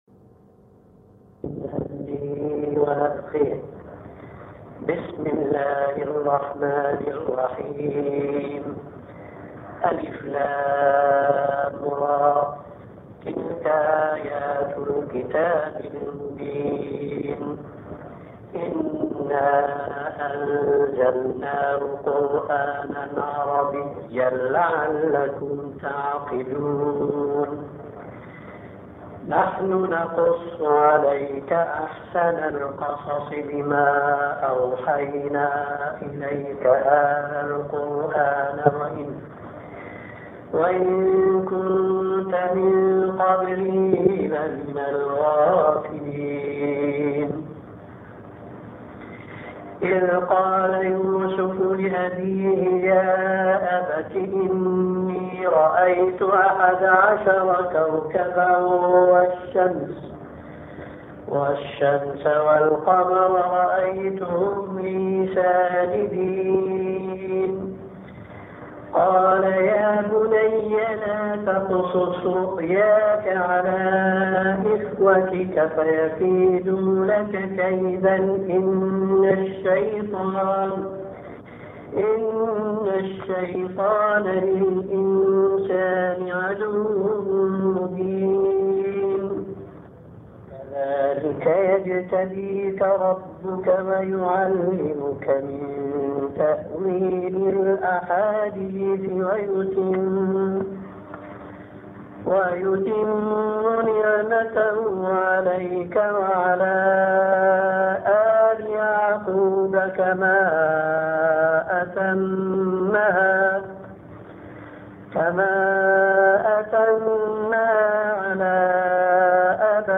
تلاوة سورة يوسف بصوت الشيخ الألباني